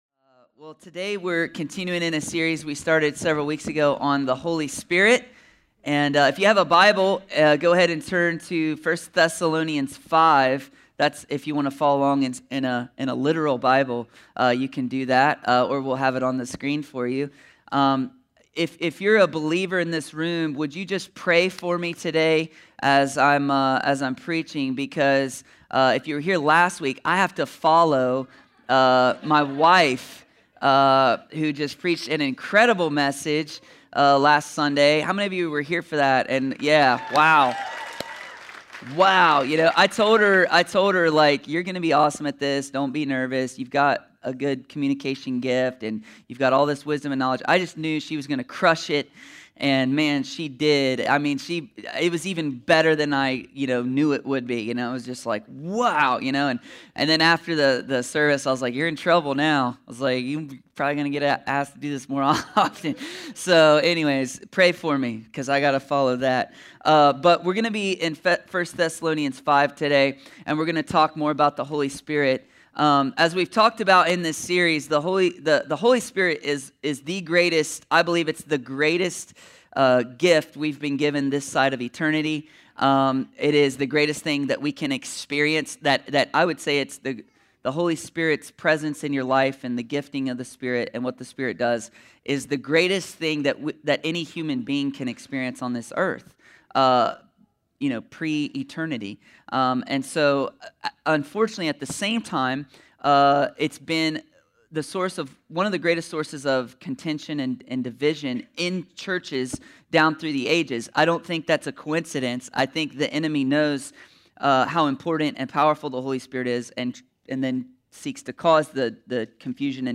A sermon from the series “The Holy Spirit.”…